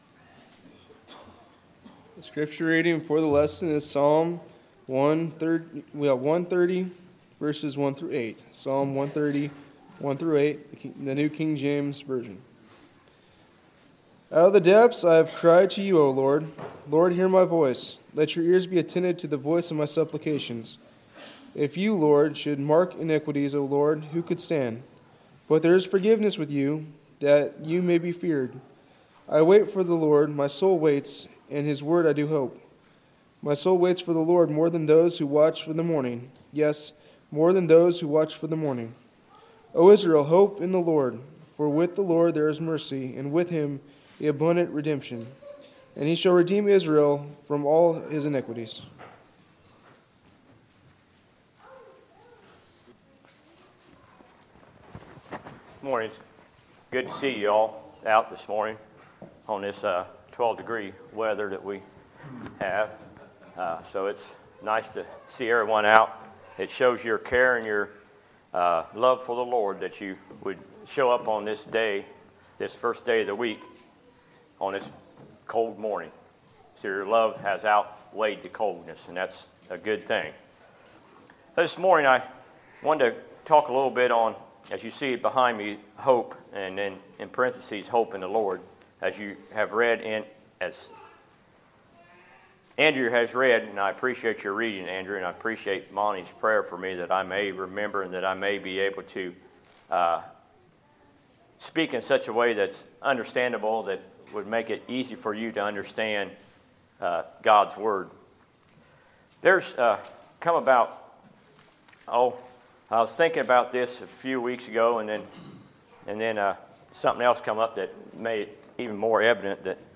Sermons, December 9, 2018